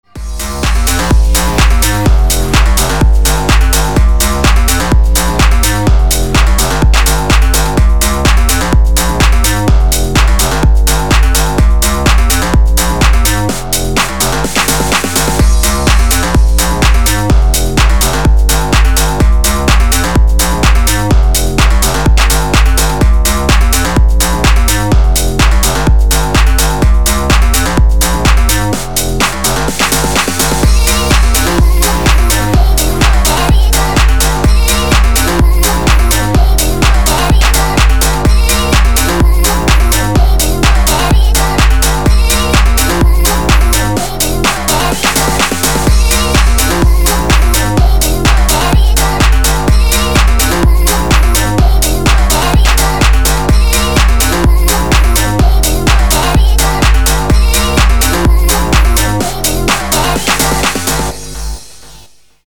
• Качество: 256, Stereo
громкие
dance
Electronic
электронная музыка
club
Стиль: future house